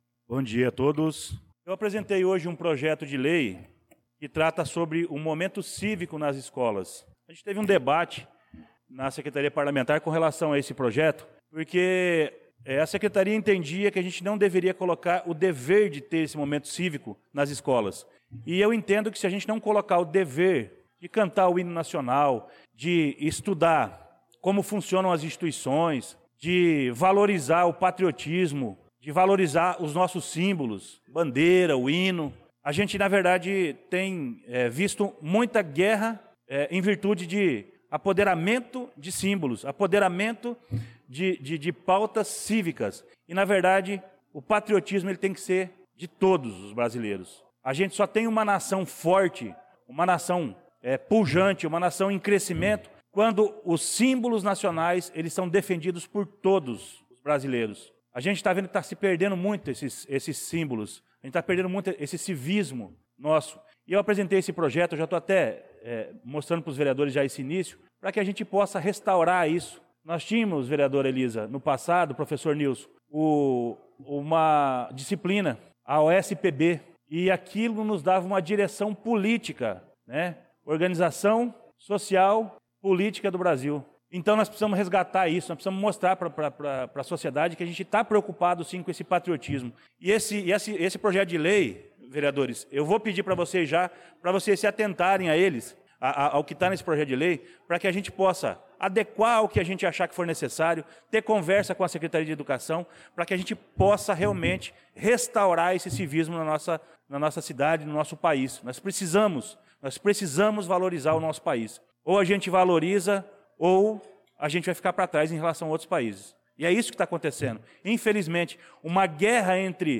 Pronunciamento do vereador Luciano Silva na Sessão Ordinária do dia 06/03/2025